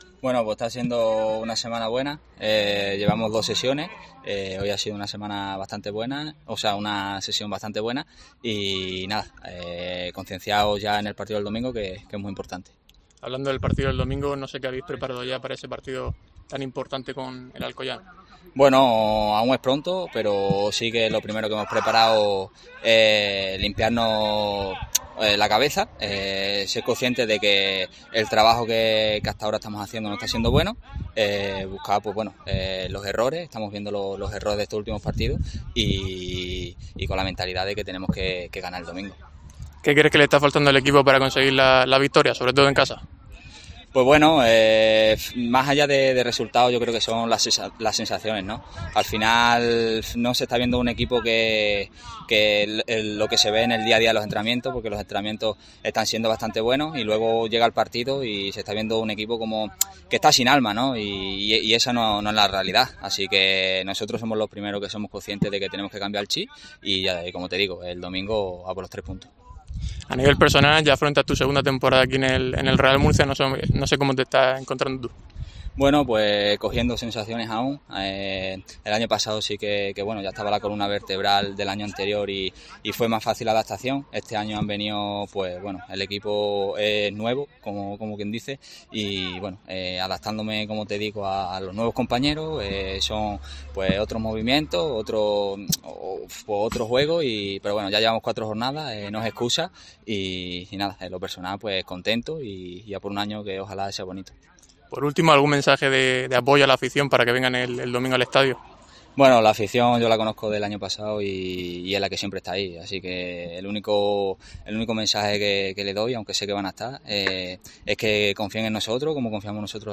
atendió a los medios de comunicación tras el entrenamiento de hoy en Pinatar Arena
comparecencia de prensa